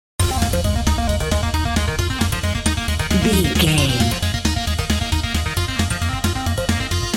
Aeolian/Minor
Fast
8bit
aggressive
synth
drums